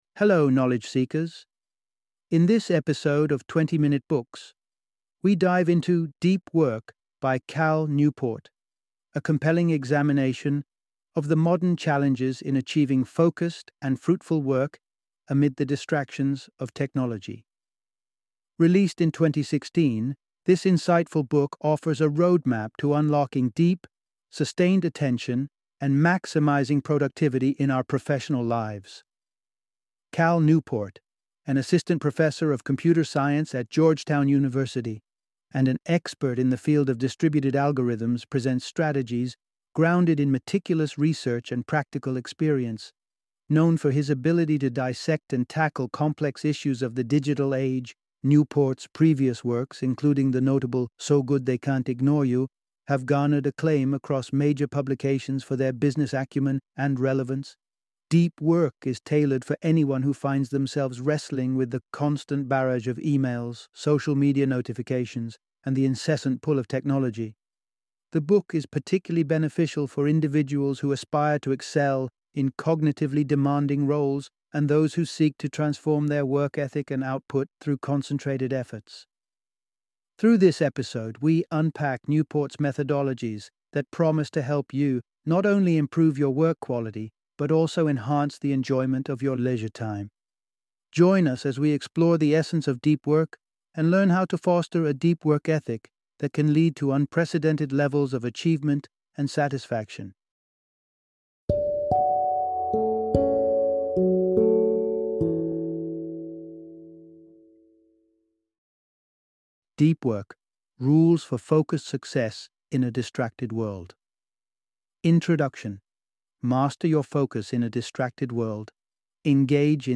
Deep Work - Audiobook Summary